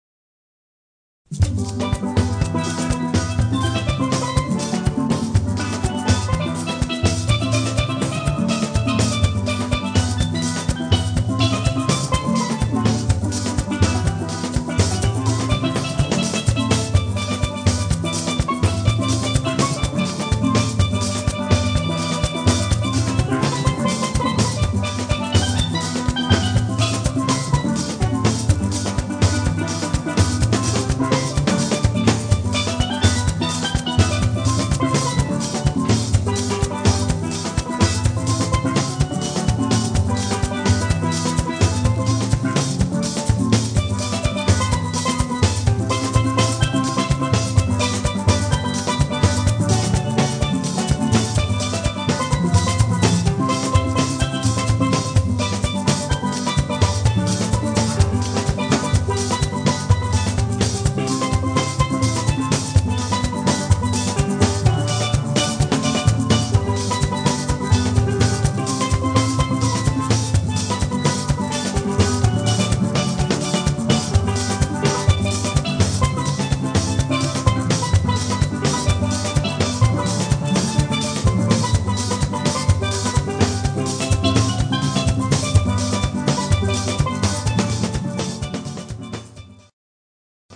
• Authentic Caribbean musicians and music
• Perfect to evoke sunny carnival ambience
• Versatile traditional steelpan ensemble